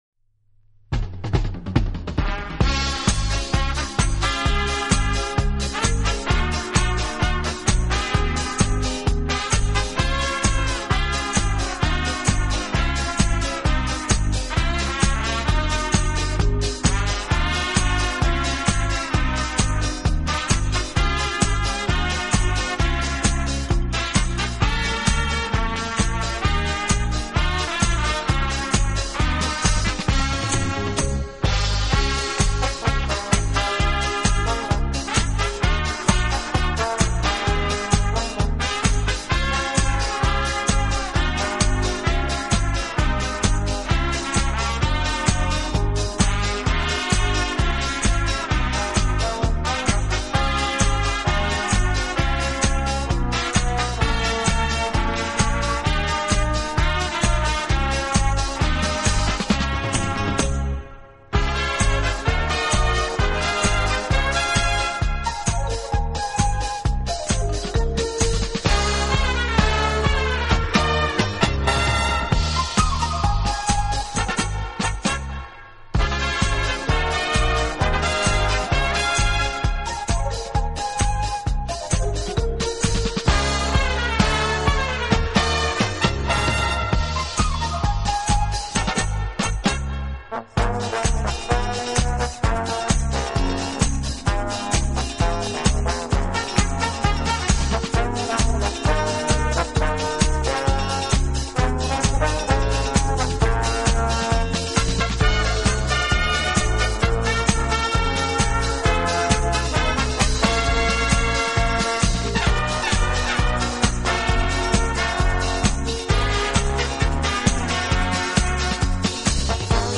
【轻音乐】
【顶级轻音乐】